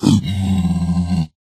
zpigangry3.ogg